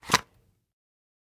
pistol_draw.ogg